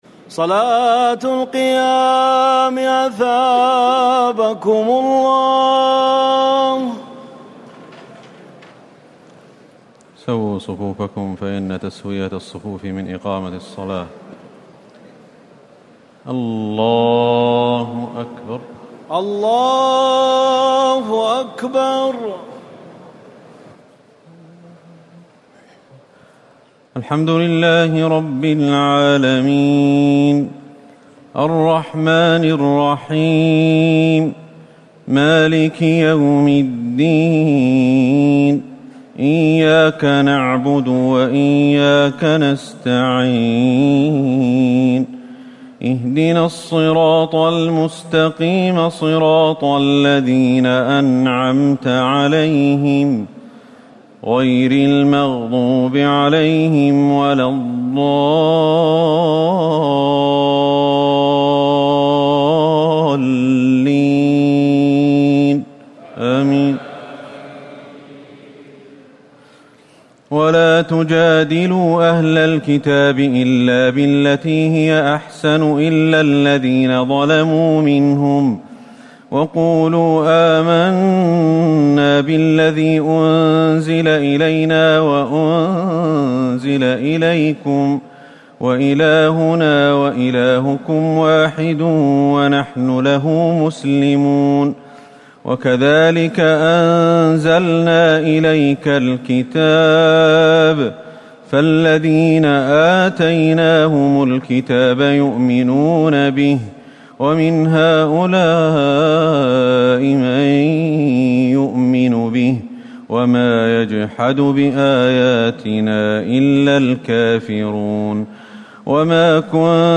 تراويح الليلة العشرون رمضان 1439هـ من سور العنكبوت (46-69) و الروم و لقمان (1-21) Taraweeh 20 st night Ramadan 1439H from Surah Al-Ankaboot and Ar-Room and Luqman > تراويح الحرم النبوي عام 1439 🕌 > التراويح - تلاوات الحرمين